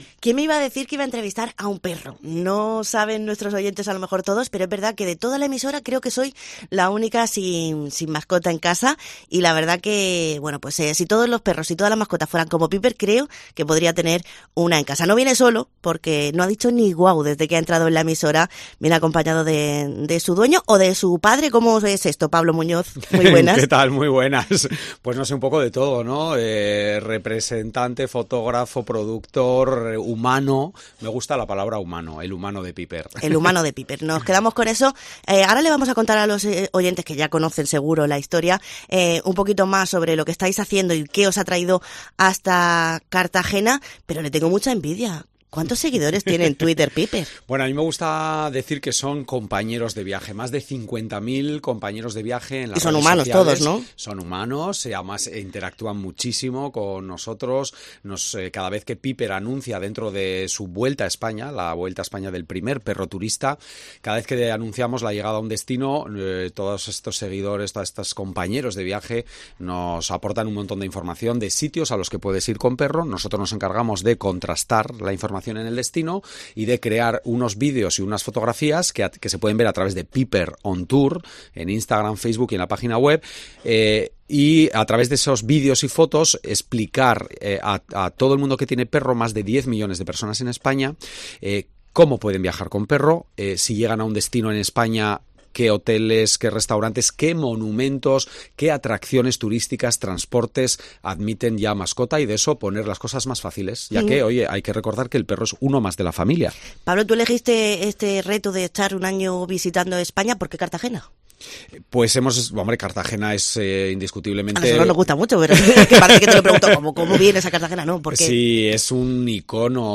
Para no faltar a la verdad ha sido difícil entrevistar al prota, porque no ha dicho ni guau por no molestar.